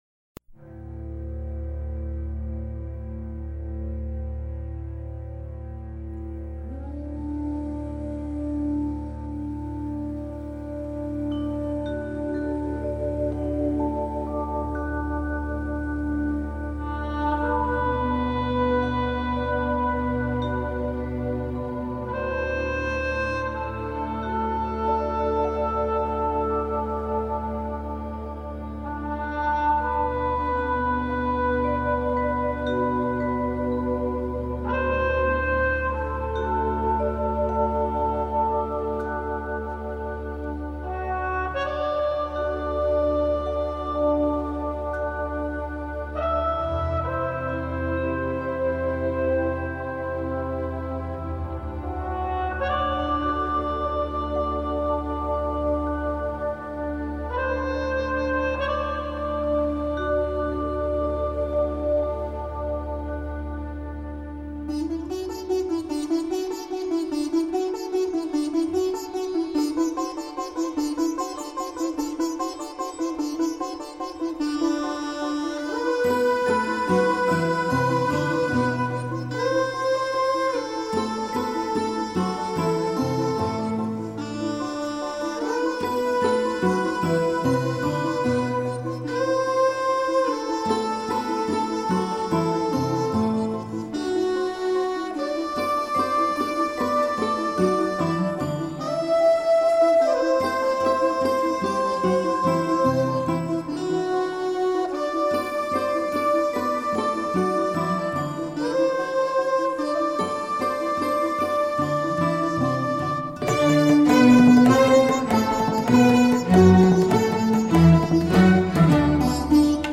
Музыка индии Индийская музыка